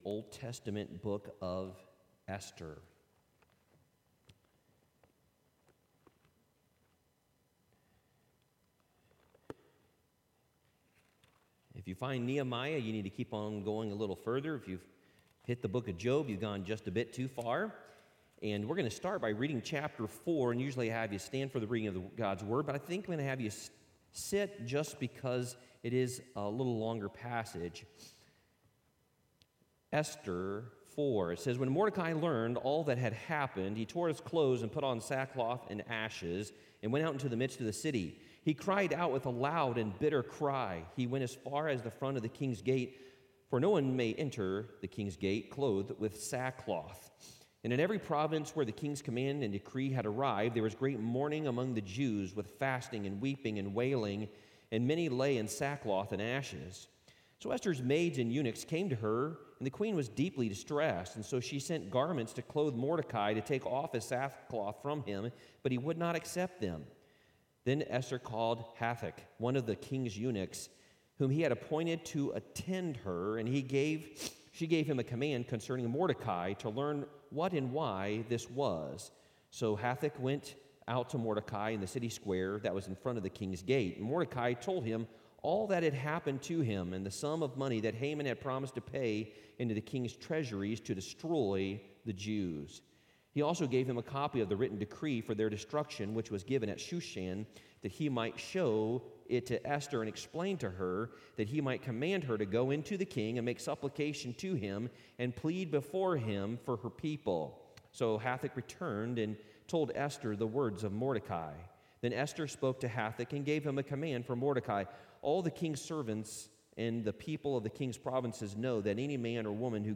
Worship Service 05/09/2021